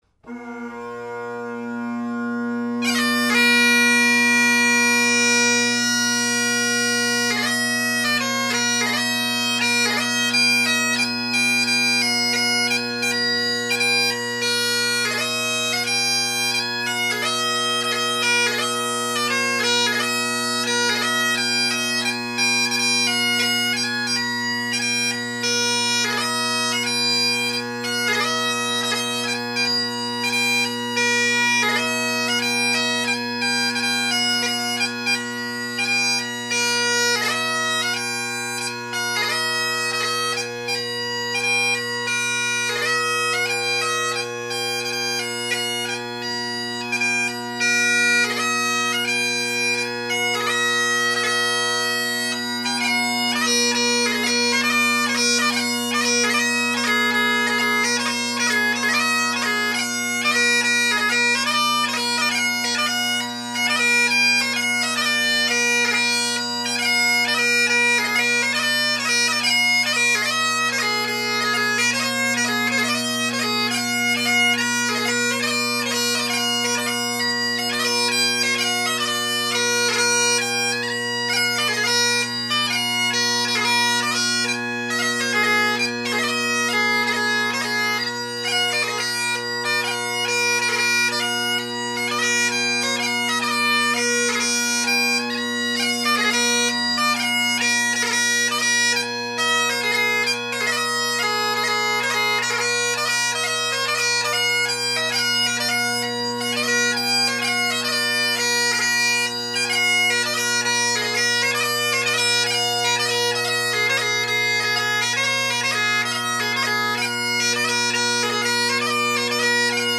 RJM solo blackwood chanter, tape only on E and high G, tuning spot on at 480 Hz with a day old Troy McAllister chanter reed that was artificially broke in with a bit of spit and a little squeeze at the tips to ease the reed and even less around the sound box to bring the pitch of the middle notes (C# and F#) up a tad.
Drone retuning as the chanter pitch went up a little or the drone reeds went flat a little, one of the two